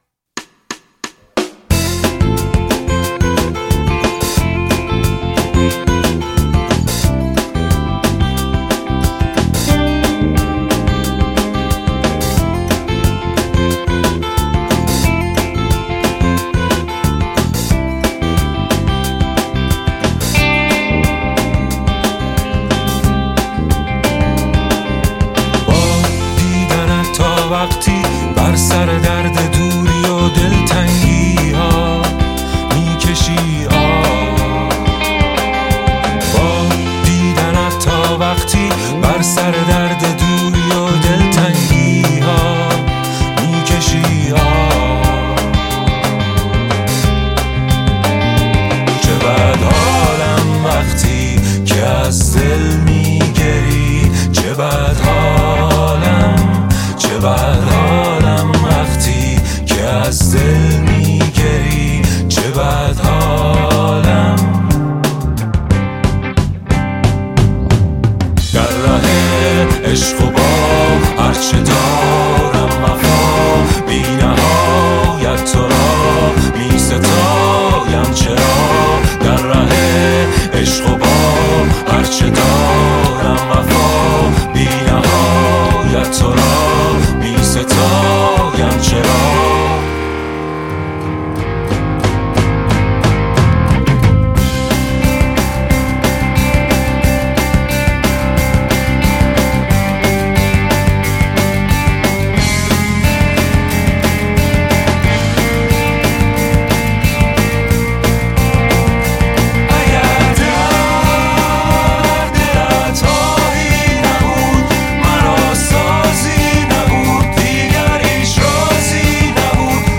Persian rock Rock Music